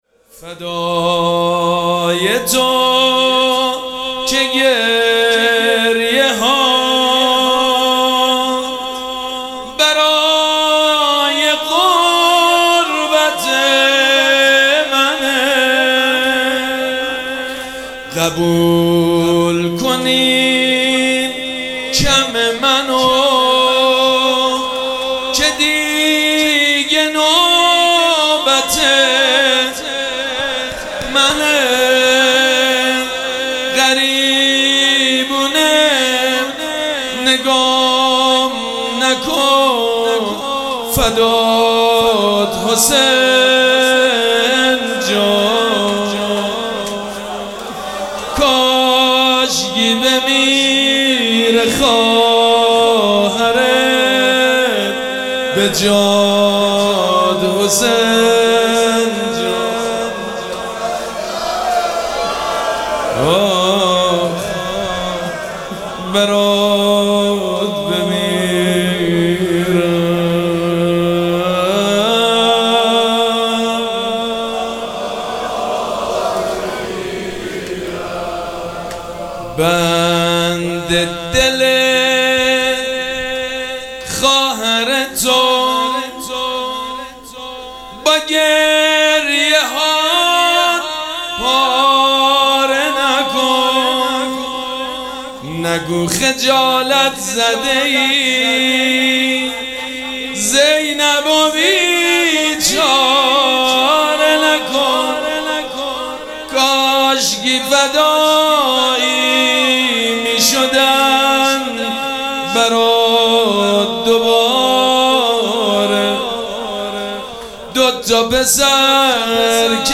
مراسم عزاداری شب چهارم محرم الحرام ۱۴۴۷
روضه
مداح